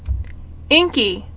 [ink·y]